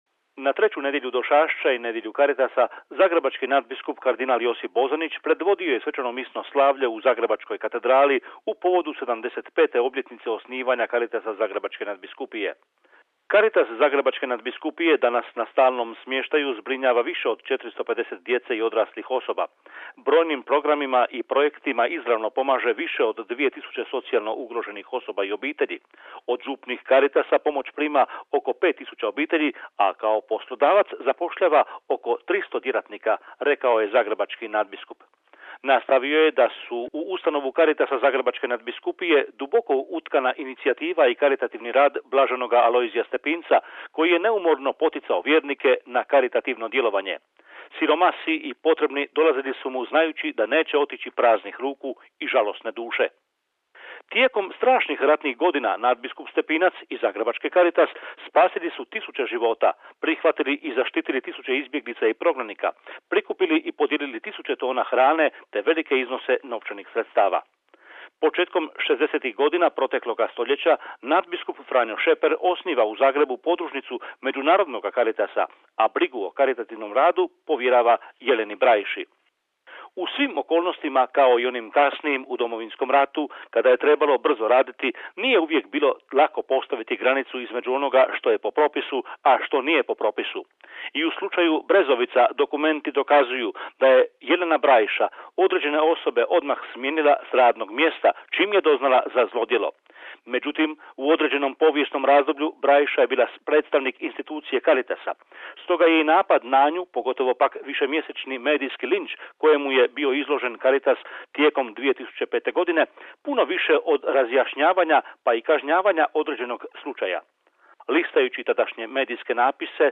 Iz Zagreba za Hrvatski program Radio Vatikana govori